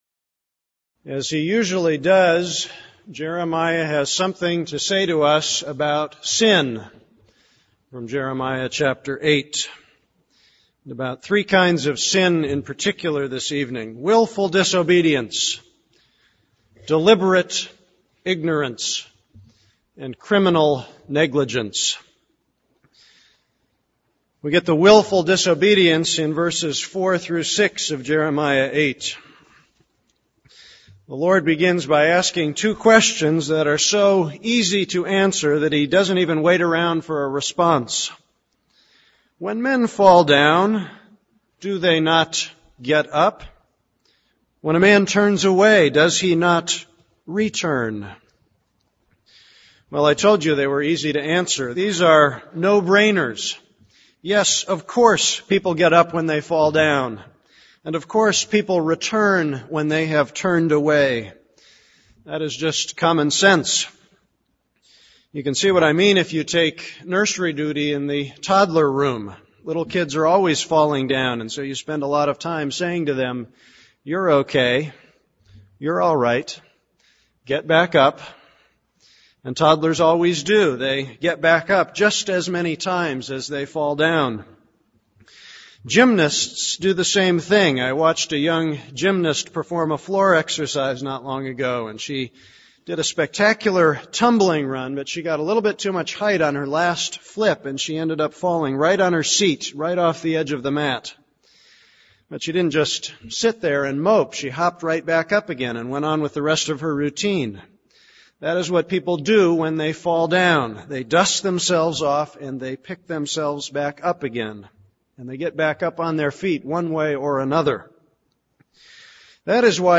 This is a sermon on Jeremiah 8:4-17.